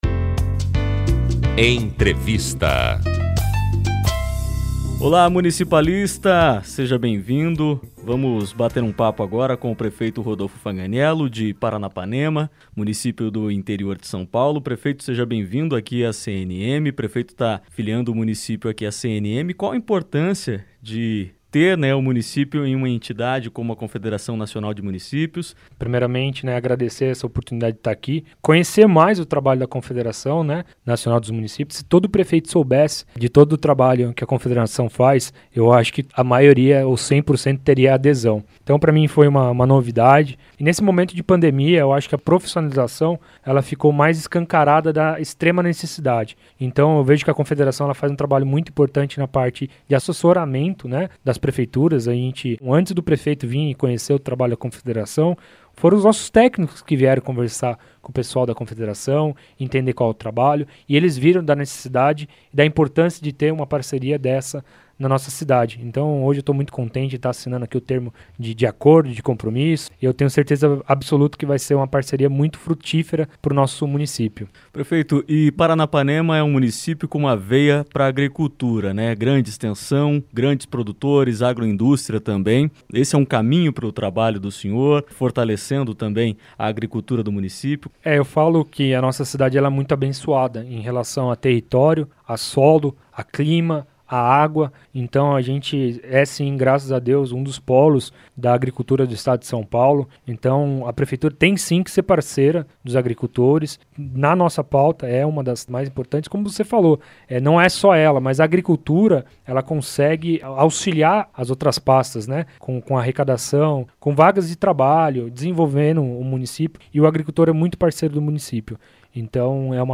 Entrevista - Prefeito Rodolfo Fanganiello Hessel - Paranapanema(SP)